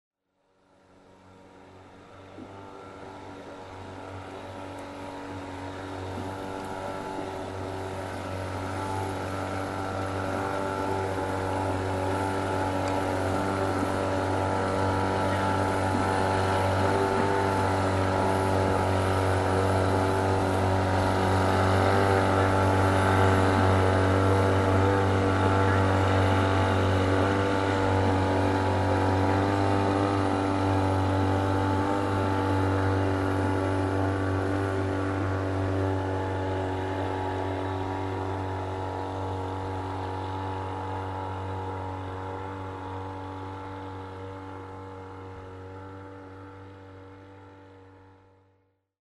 Шум мотора проплывающей лодки